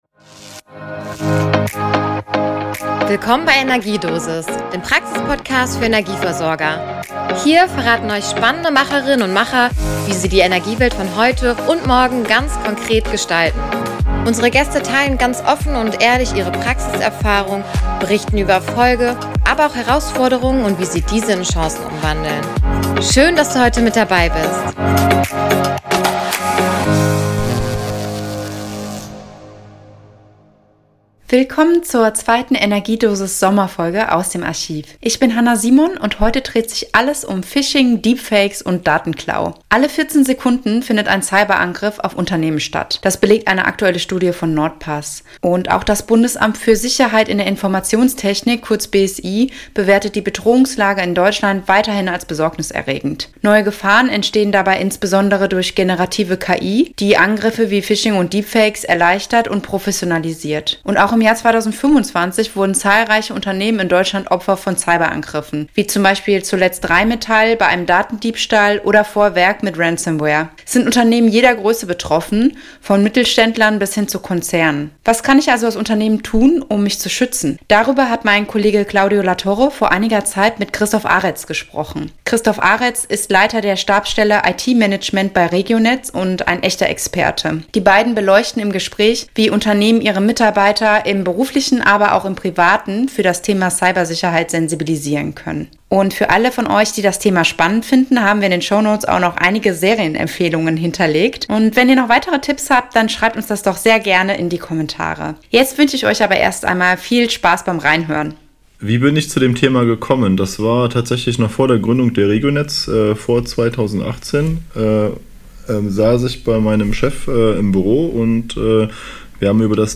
Ein Gespräch über Sicherheitskultur, Vertrauen und die zentrale Rolle des Menschen in der IT-Sicherheit.